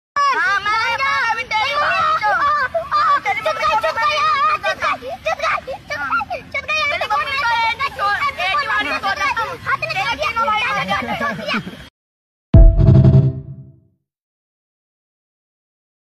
Aaaa Chud Gayi! Hindi Exclamation Sound Effect
A distinct Hindi exclamation, 'Aaaa Chud Gayi,' often used to express frustration, annoyance, or a moment of realization about a difficult situation.
aaaa-chud-gayi-hindi-exclamation-sound-effect-1dd9ea37.mp3